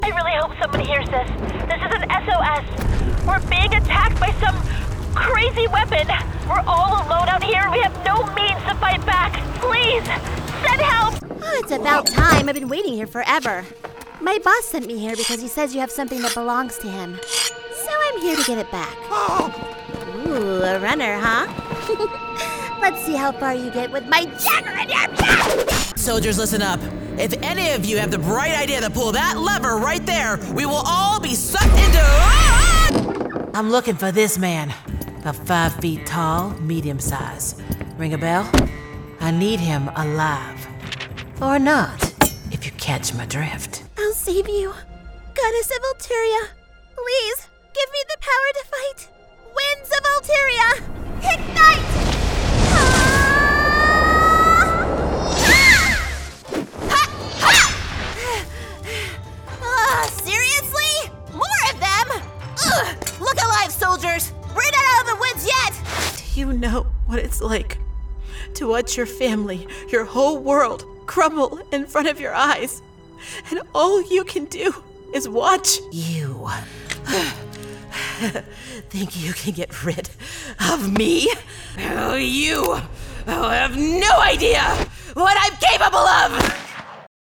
GAMING 🎮